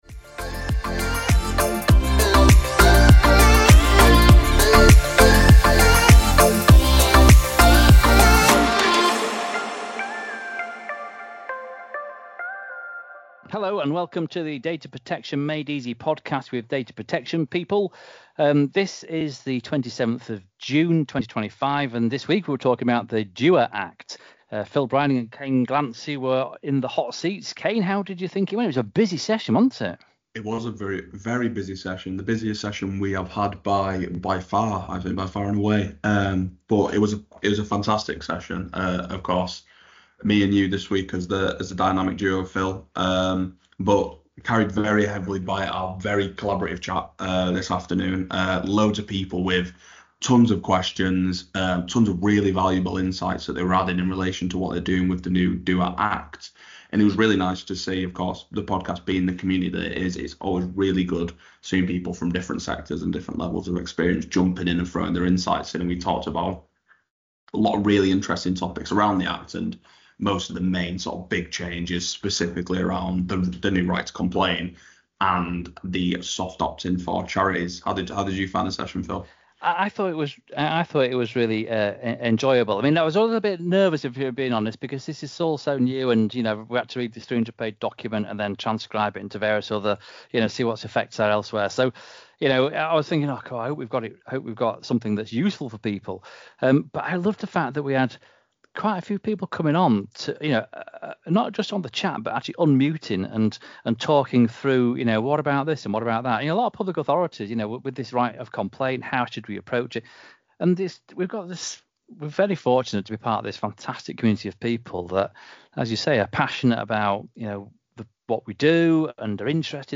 Together, they break down the key changes introduced by the Act, how it evolved from the DPDI Bill, and what it means for both public and private sector organisations. Tune in to hear expert insights, practical takeaways, and the community’s live reactions to one of the most significant updates in UK data protection law since the GDPR.